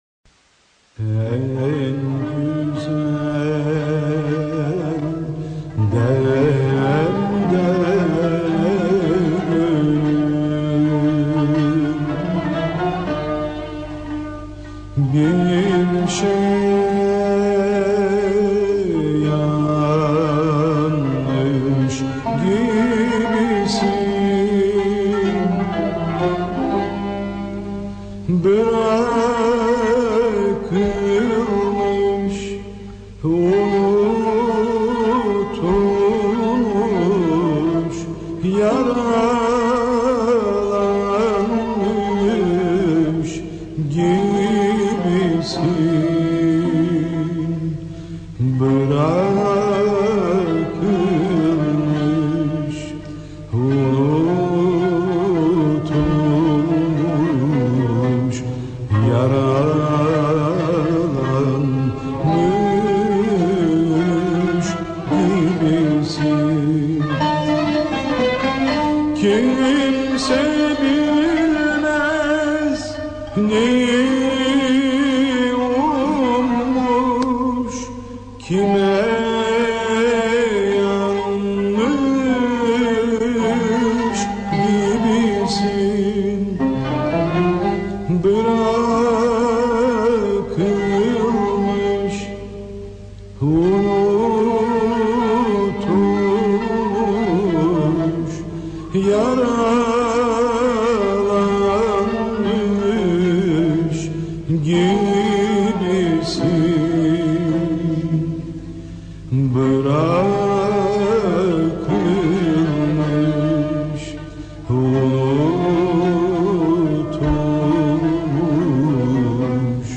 Makam: Uşşak Form: Şarkı Usûl
Usûl: Aksak